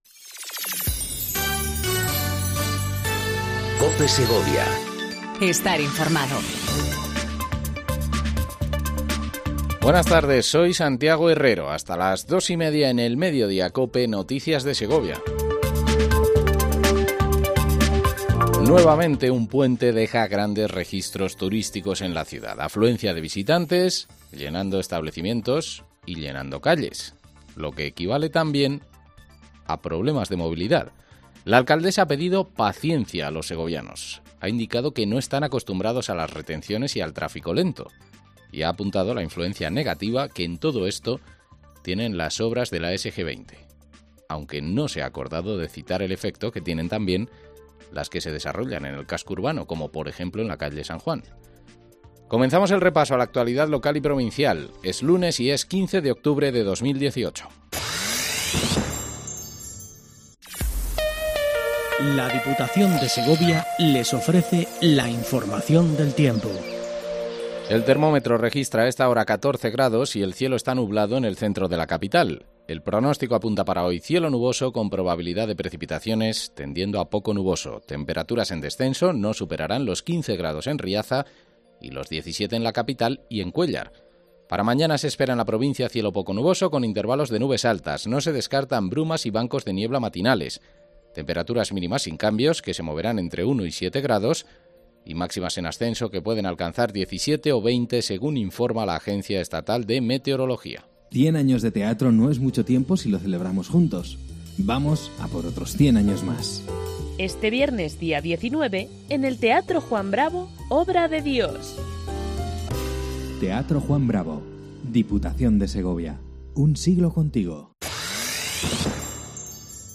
INFORMATIVO MEDIODÍA COPE EN SEGOVIA 14:20 DEL 15/10/18